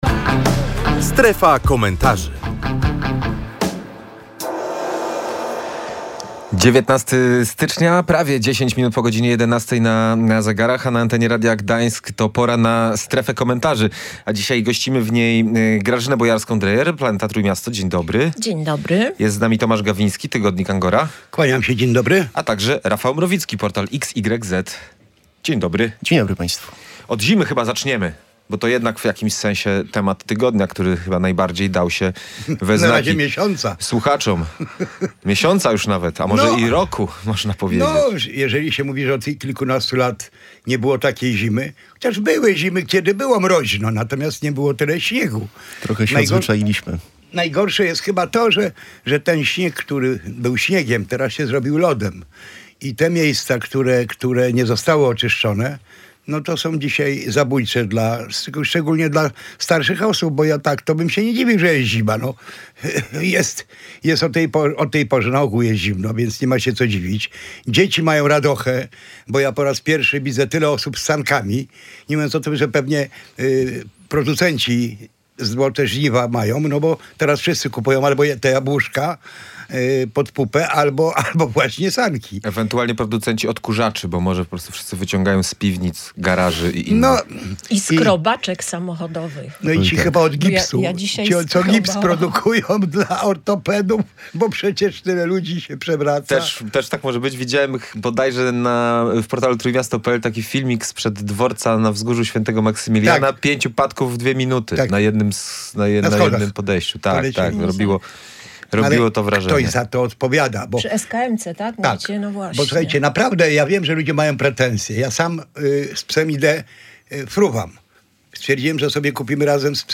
Dyskusja o odpowiedzialności za odśnieżanie